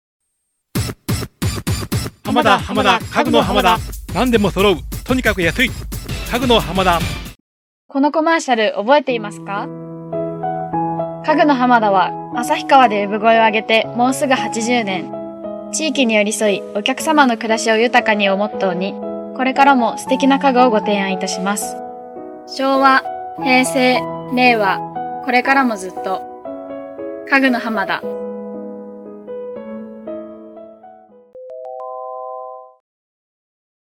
街頭放送CMの専門家
音の広告　街頭放送